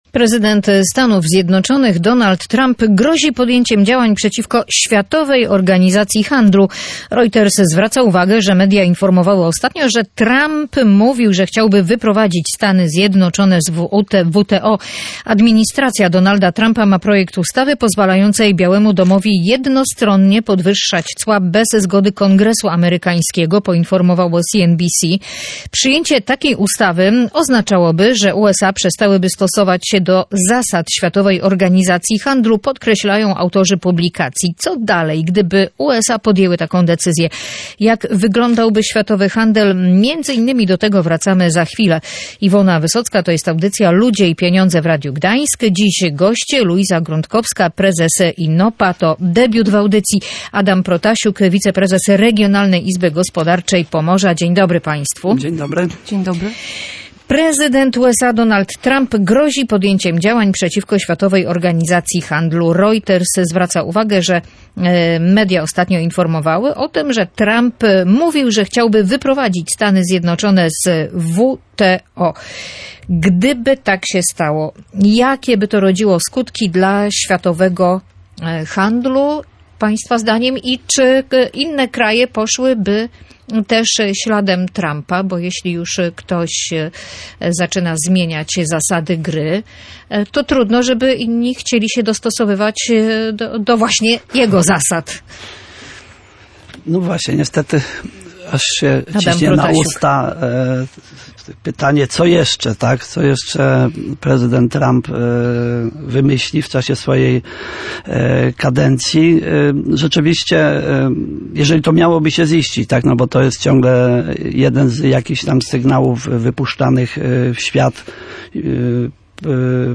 Przyjęcie jej oznaczałoby sprzeciwienie się zasadom Światowej Organizacji Handlu (WTO). O możliwym wyjściu Stanów Zjednoczonych z WTO rozmawiali eksperci audycji Ludzie i Pieniądze.